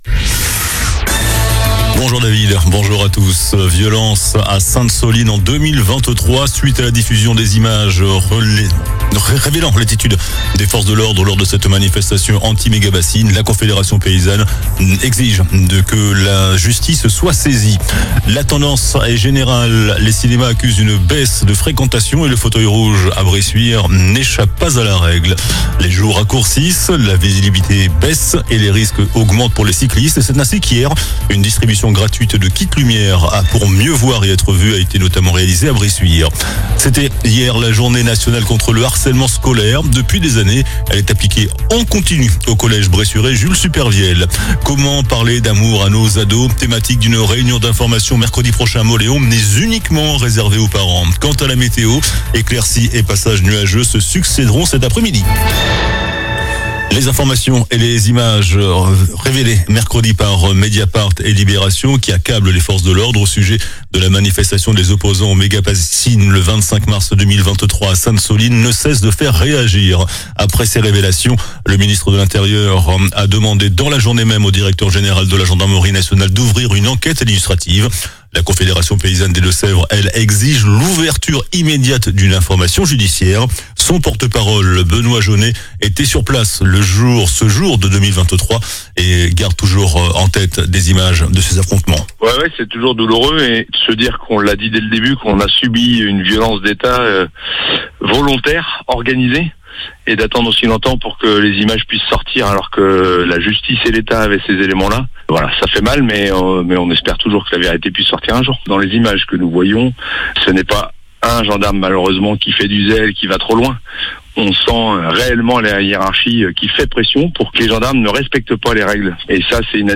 JOURNAL DU VENDREDI 07 NOVEMBRE ( MIDI )